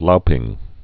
(loupĭng, lō-)